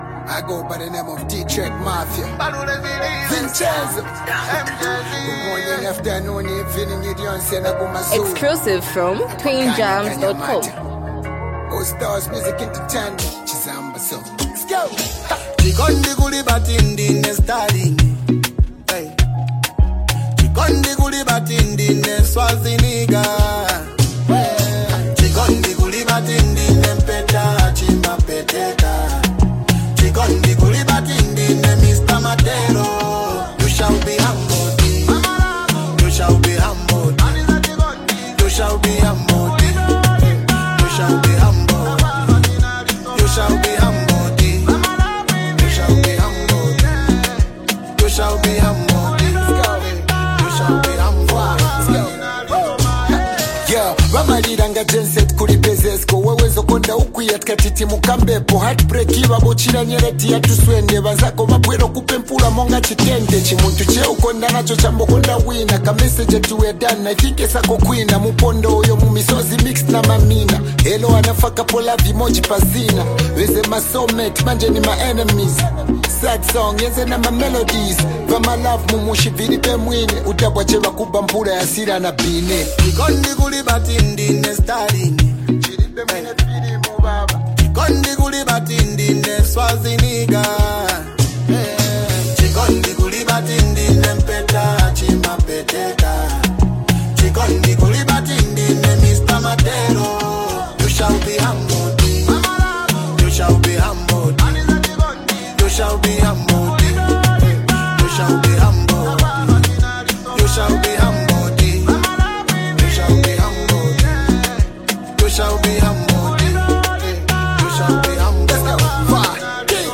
a bold and confident anthem
combining rap intensity with melodic appeal.